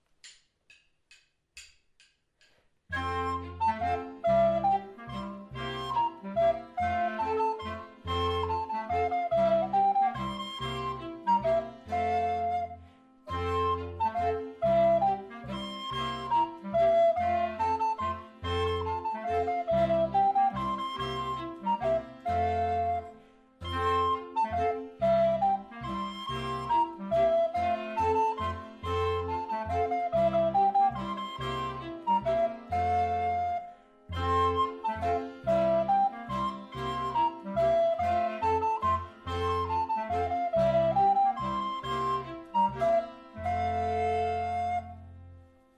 SPRINGT DA HIRSCH ÜBERN BACH - Begleitsatz mit Melodie Herzlichen Dank an die "Hofmarkmusik" für die Aufnahmen!